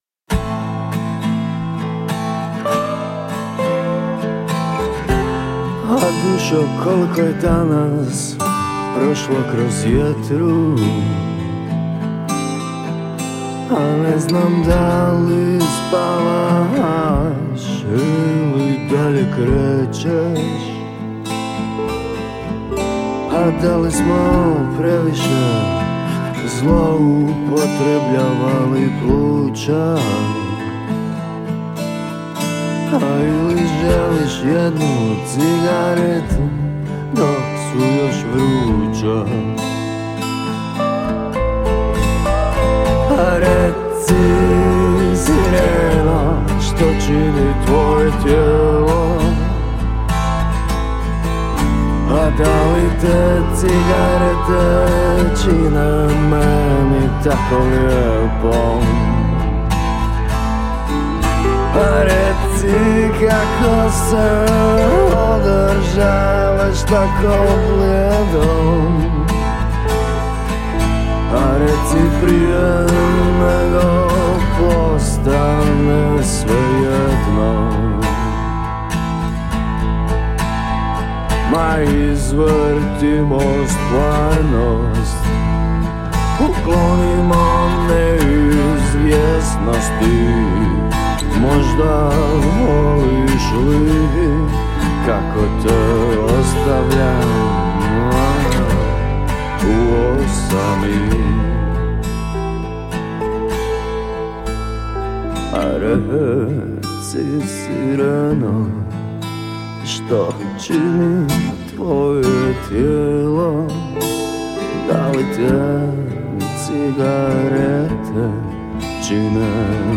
stvarajući alter rock sentiš bez bubnja.